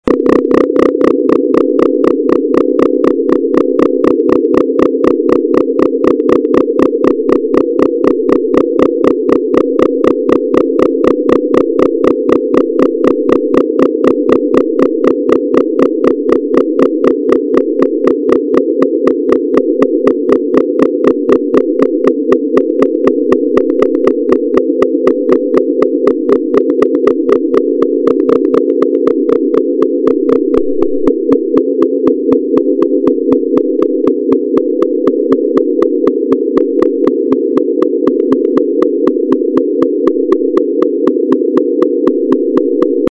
Sonification 4c: Sine oscillators on attack
It still is quite muddle in the middle, but has a kind of bad B-movie theremin vibe: